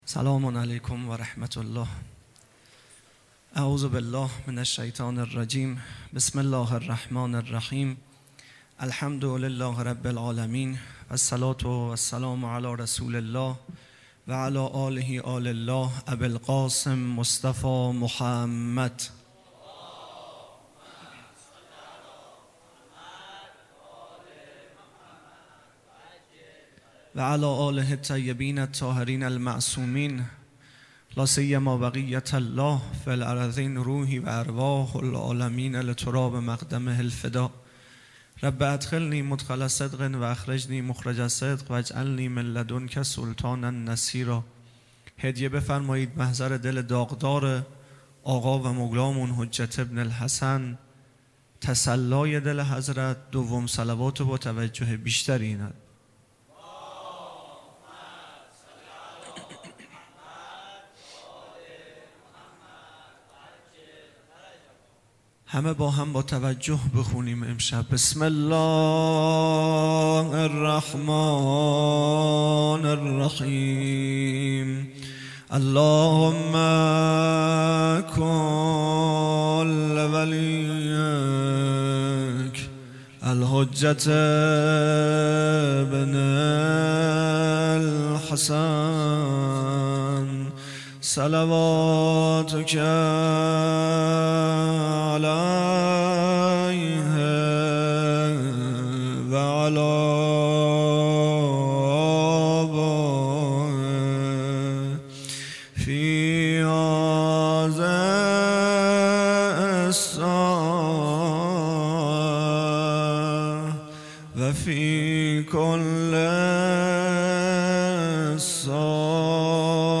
خیمه گاه - هیئت بچه های فاطمه (س) - سخنرانی | ۵ مرداد ماه ۱۴۰۲